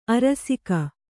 ♪ arasika